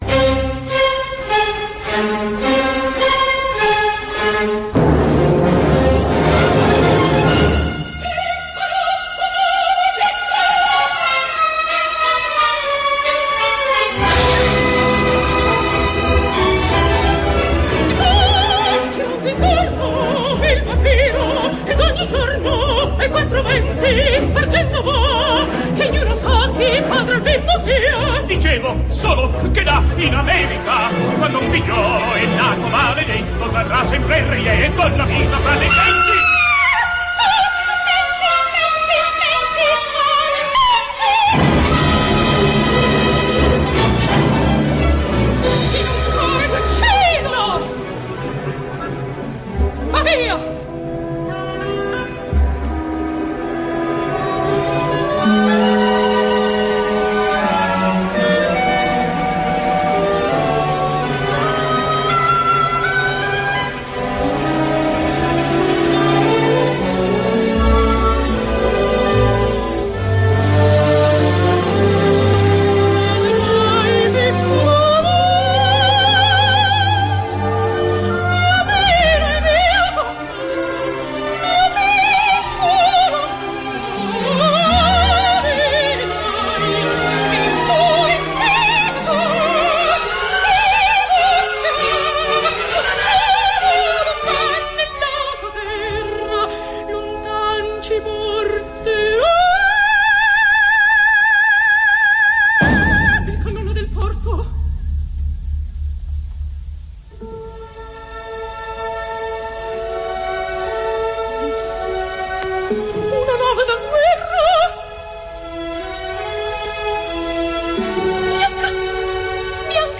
registrazione concerto radio.